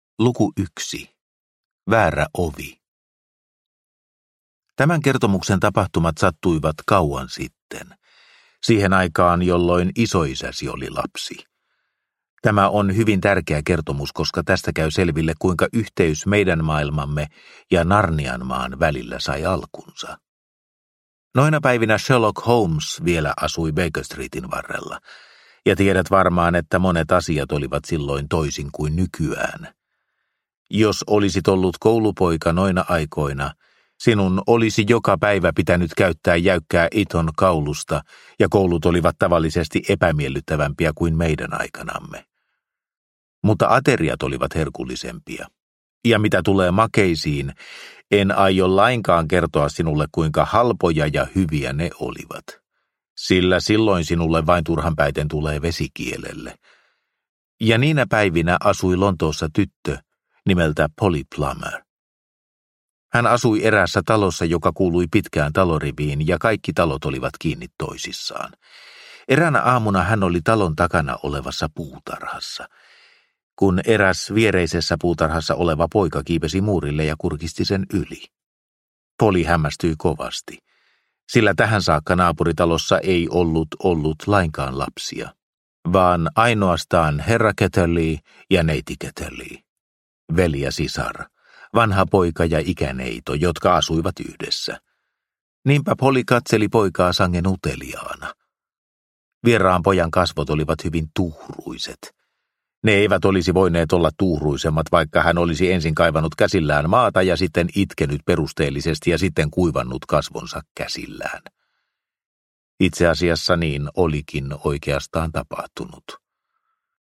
Taikurin sisarenpoika – Ljudbok – Laddas ner